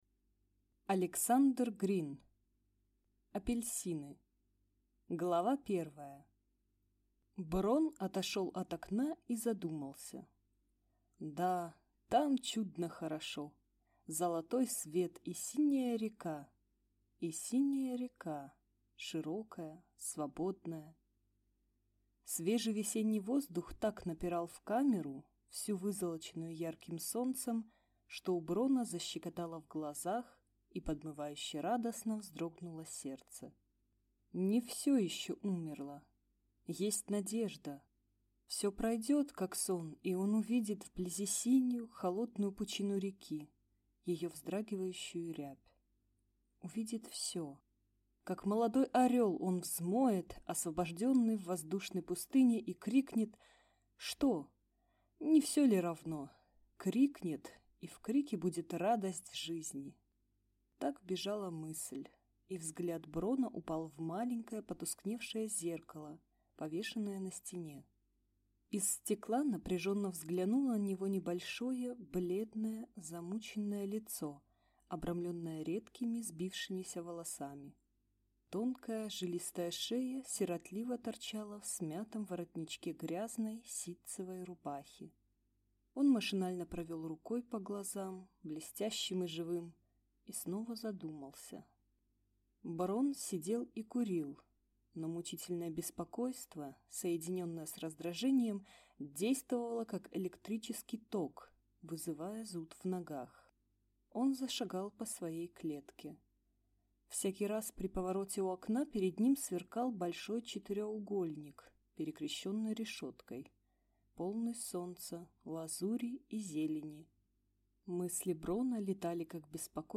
Аудиокнига Апельсины - Скачать книгу, слушать онлайн